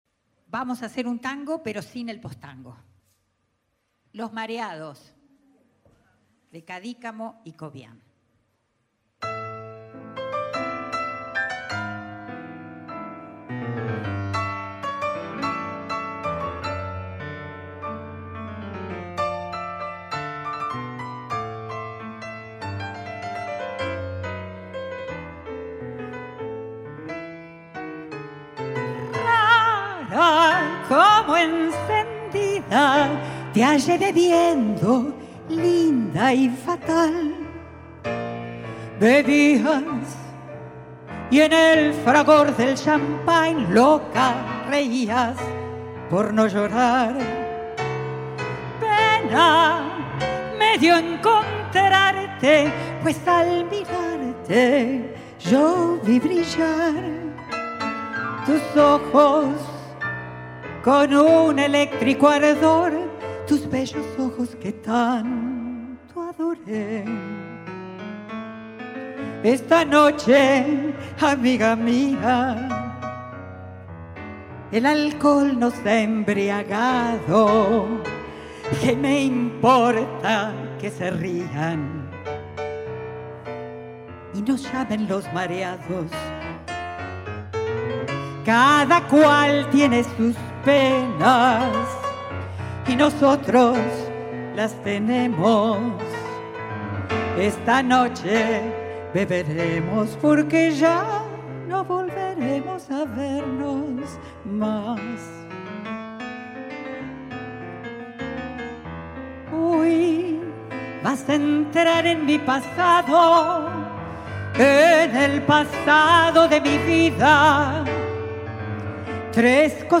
Temporada de Música de Cámara 2026.
Voz
Piano
Grabación realizada por el equipo de exteriores de las Radios Públicas el 9 de abril de 2026 en el Auditorio Vaz Ferreira.